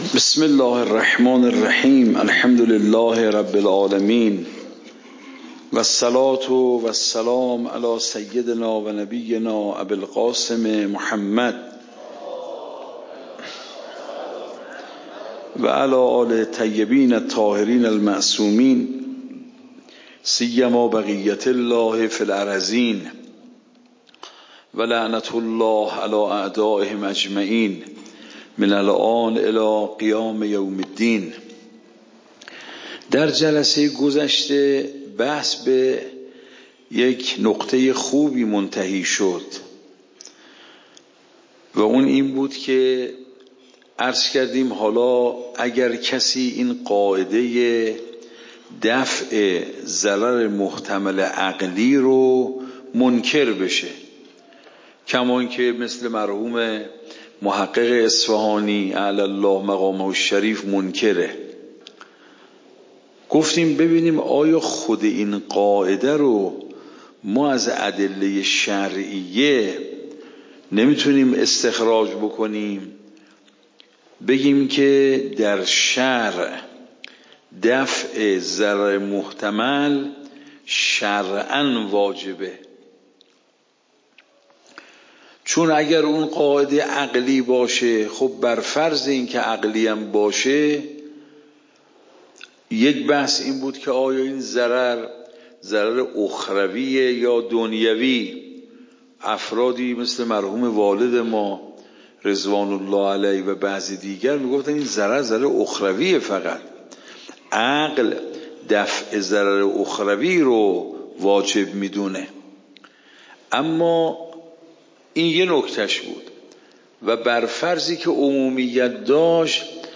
صوت درس